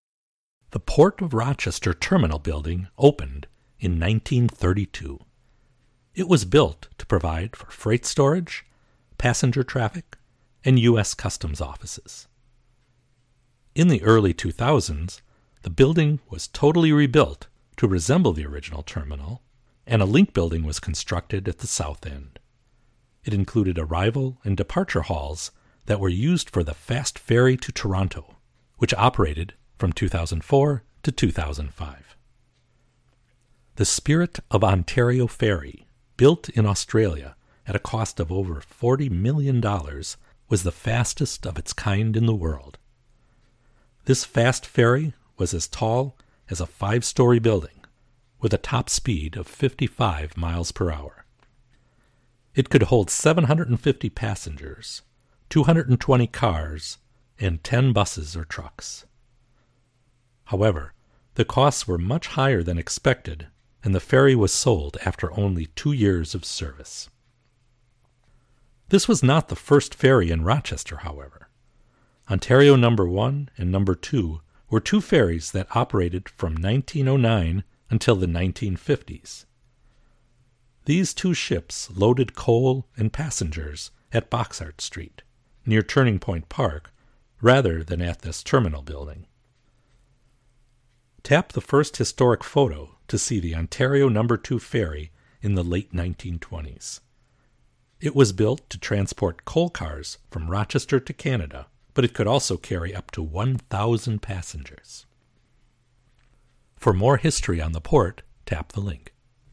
This narrated walking tour begins at Lake Ontario Park, and follows the west bank of the  Genesee River from Lake Ontario to the O’Rorke Lift Bridge.